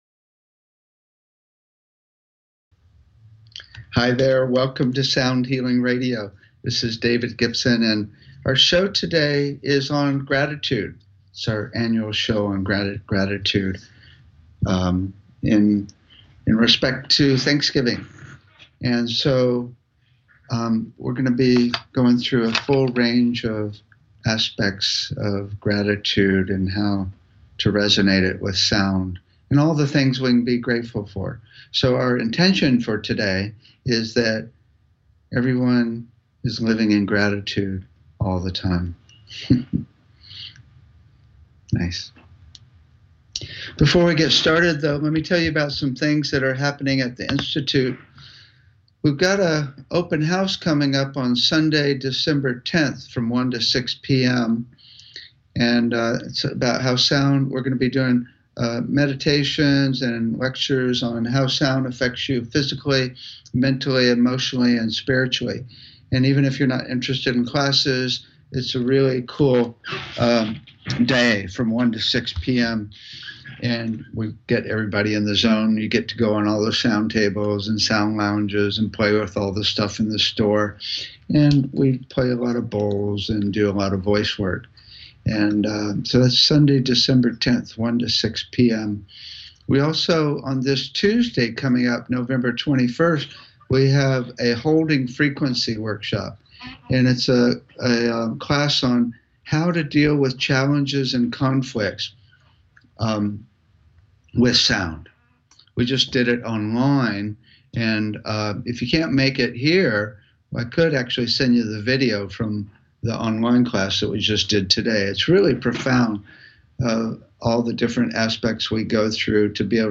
Talk Show Episode, Audio Podcast, Sound Healing and About Gratitude on Thanksgiving, using sound techniques on , show guests , about About Gratitude,Gratitude on Thanksgiving,using sound techniques, categorized as Health & Lifestyle,Energy Healing,Sound Healing,Kids & Family,Music,Philosophy,Psychology,Self Help,Spiritual